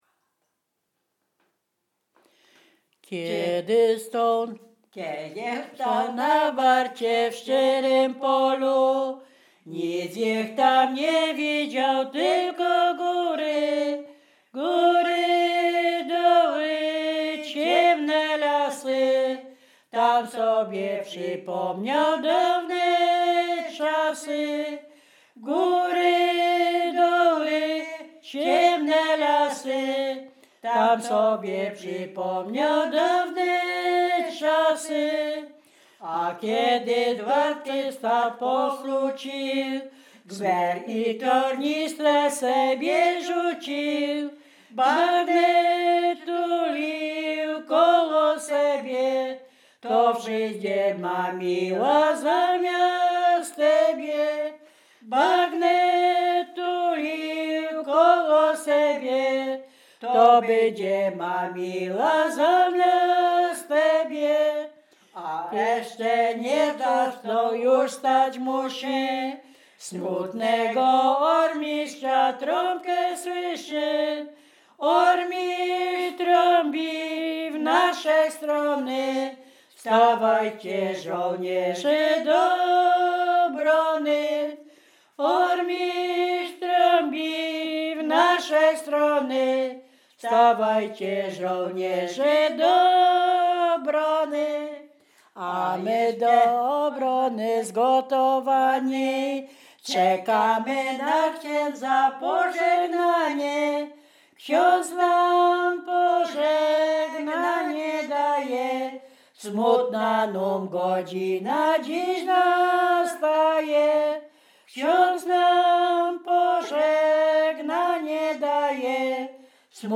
Bukowina Rumuńska
województwo dolnośląskie, powiat lwówecki, gmina Lwówek Śląski, wieś Zbylutów